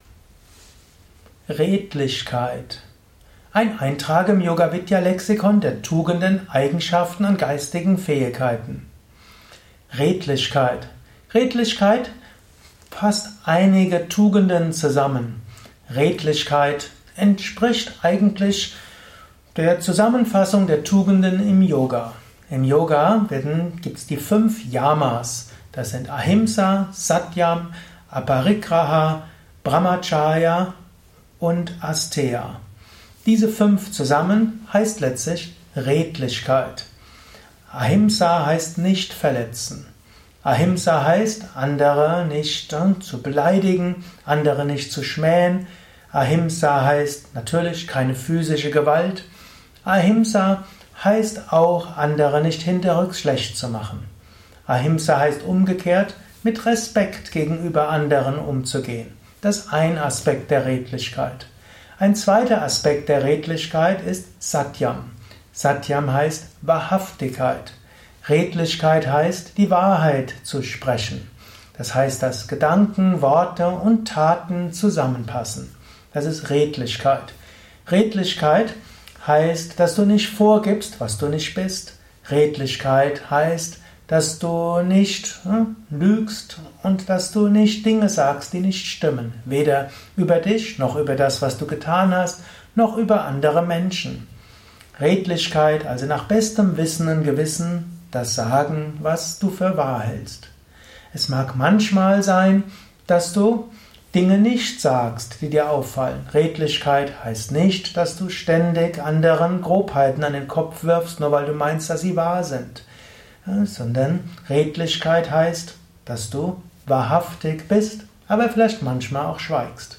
Lausche einem Vortrag über Redlichkeit, eine Tugend, eine Eigenschaft, eine geistige Fähigkeit.
Dies ist die Tonspur eines Videos zur geistigen Eigenschaft Redlichkeit.